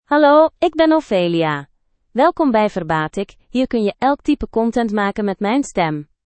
Ophelia — Female Dutch (Belgium) AI Voice | TTS, Voice Cloning & Video | Verbatik AI
Ophelia is a female AI voice for Dutch (Belgium).
Voice: OpheliaGender: FemaleLanguage: Dutch (Belgium)ID: ophelia-nl-be-google
Voice sample
Listen to Ophelia's female Dutch voice.
Ophelia delivers clear pronunciation with authentic Belgium Dutch intonation, making your content sound professionally produced.